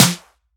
Subtle Reverb Snare Drum Sample F Key 319.wav
Royality free snare drum sound tuned to the F note.
.WAV .MP3 .OGG 0:00 / 0:01 Type Wav Duration 0:01 Size 147,06 KB Samplerate 44100 Hz Bitdepth 24 Channels Stereo Royality free snare drum sound tuned to the F note.
subtle-reverb-snare-drum-sample-f-key-319-anz.mp3